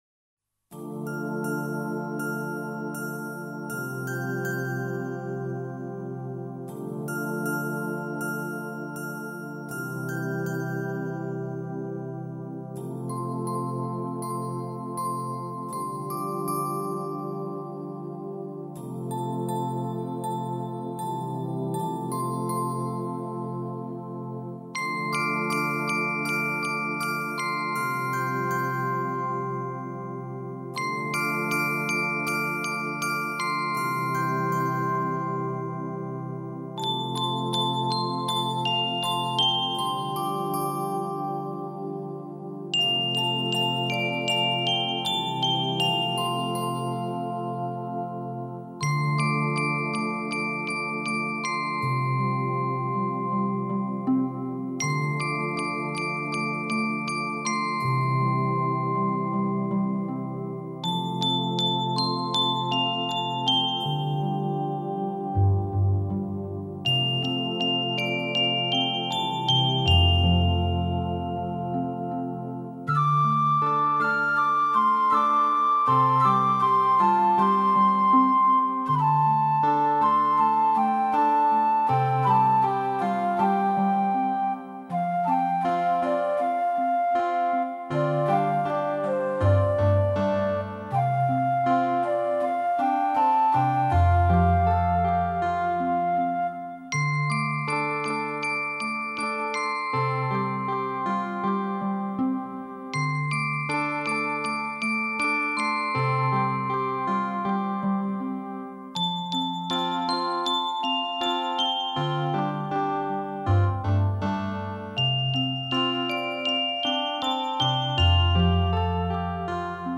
水晶琴闪烁着最清心无欲的音符，以简单、真朴的旋律，